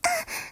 moan5.ogg